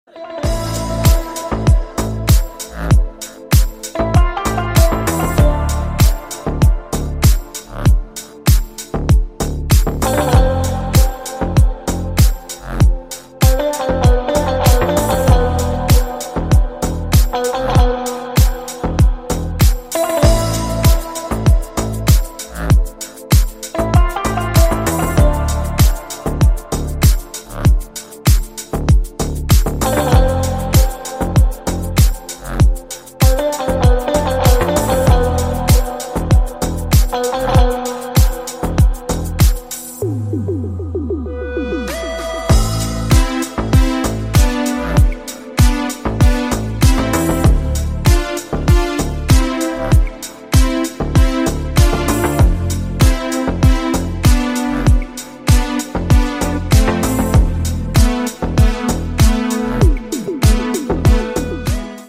Спокойный Deep под рингтон